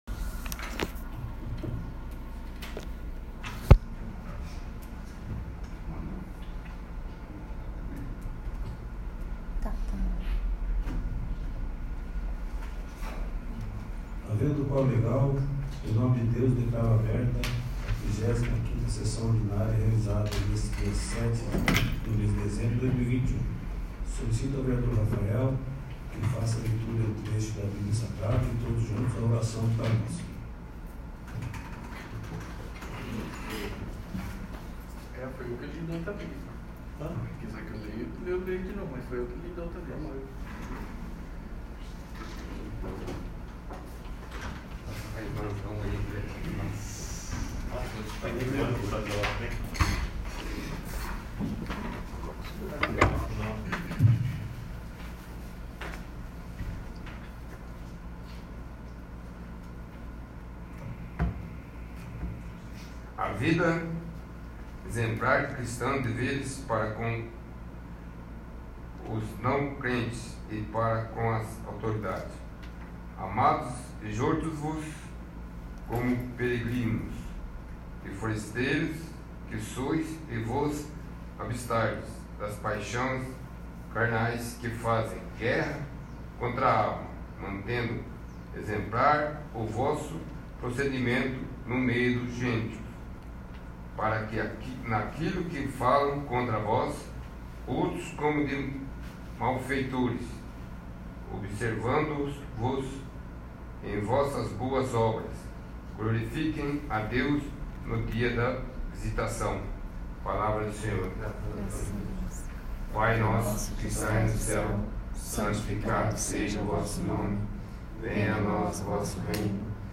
35º. Sessão Ordinária